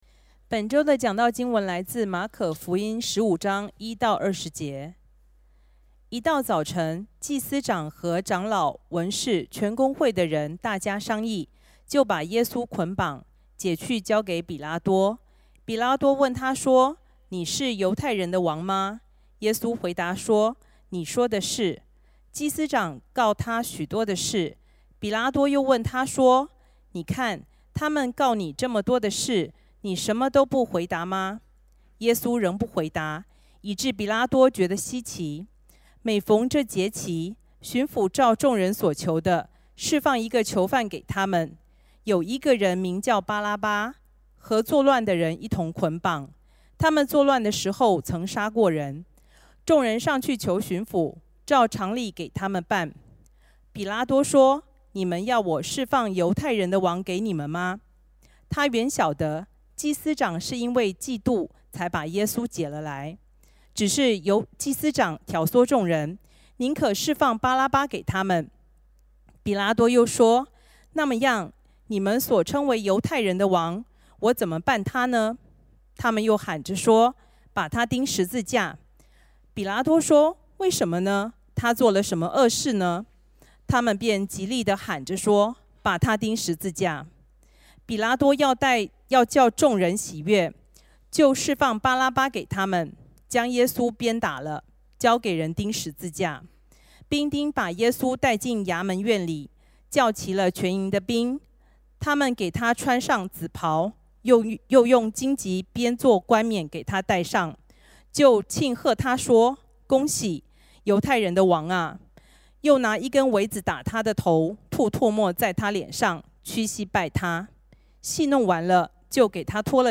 主日证道音频 讨论问题 上帝的儿子似乎因为世人不义的裁决而进入可怕的死刑之旅，我们在跟随基督的道路上，是否也会遭遇同样的命运你？